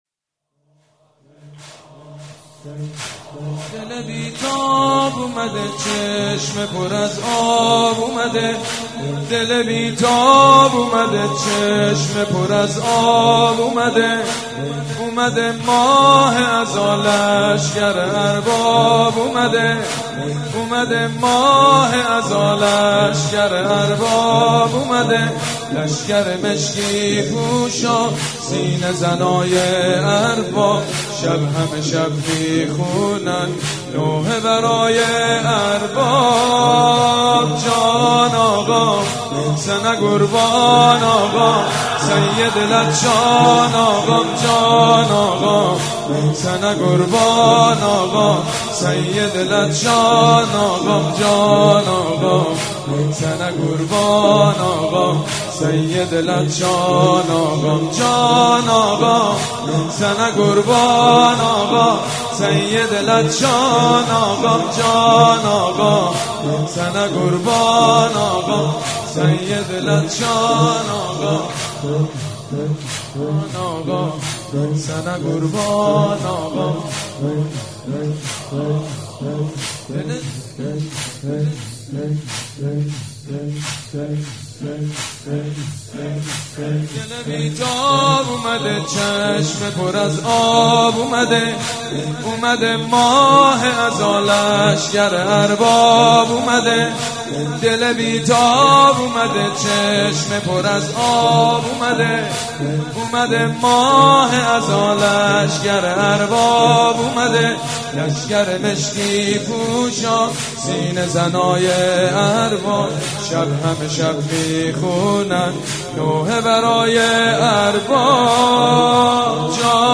دانلود مداحی دل بی تاب اومده چشم پر آب اومده/ سید مجید بنی فاطمه